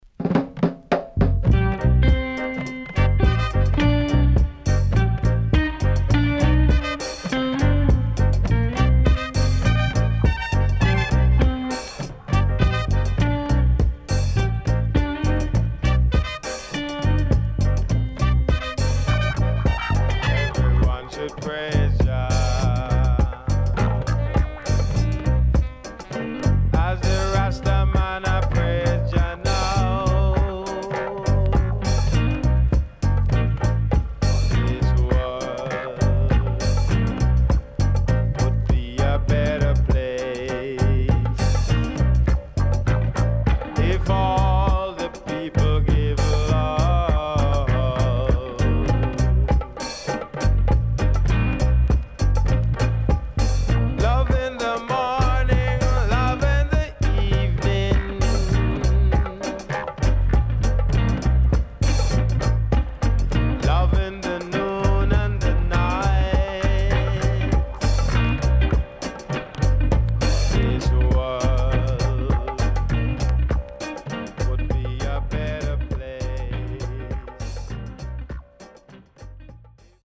SIDE B:少しノイズ入ります。